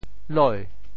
“礼”字用潮州话怎么说？
礼（禮） 部首拼音 部首 礻 总笔划 5 部外笔划 1 普通话 lǐ 潮州发音 潮州 loi2 白 li2 文 中文解释 礼 <动> (会意。